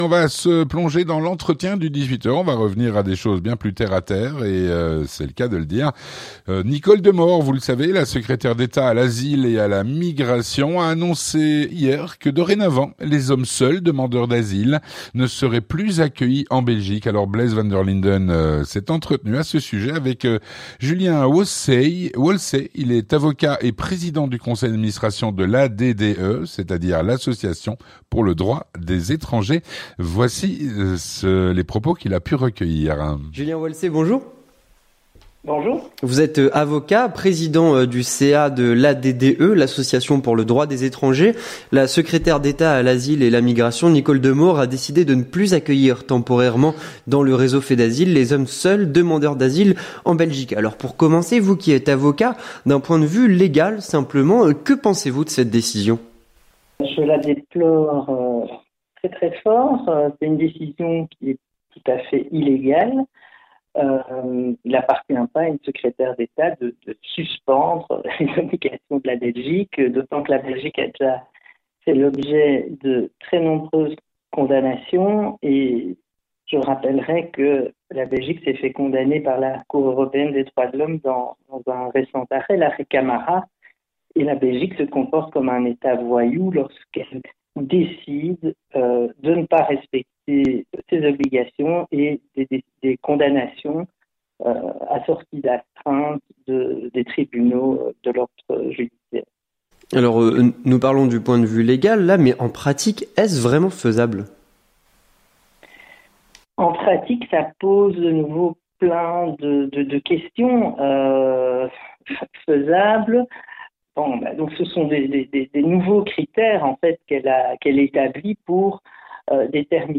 L'entretien du 18h - les hommes seuls, demandeurs d'asile, ne seront plus accueillis en Belgique.